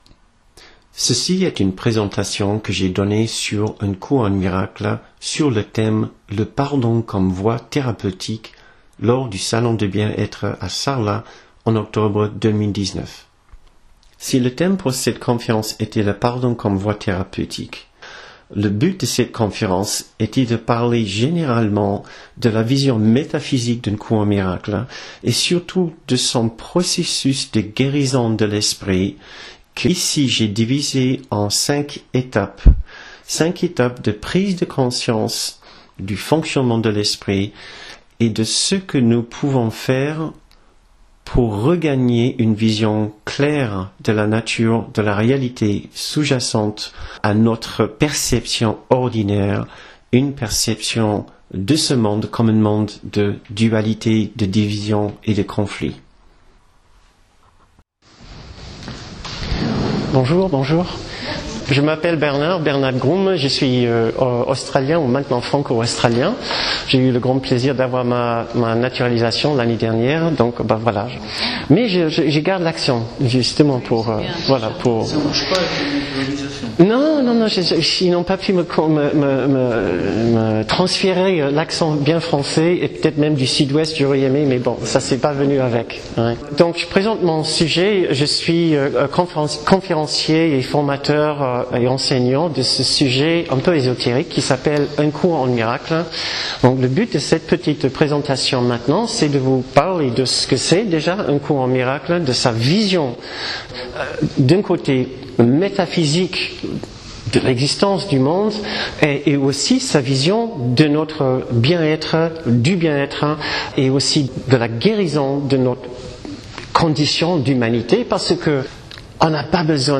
confc3a9rence-dintroduction-le-pardon-comme-voie-thc3a9rapeutique-1.mp3